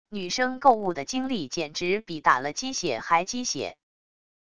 女生购物的精力简直比打了鸡血还鸡血wav音频生成系统WAV Audio Player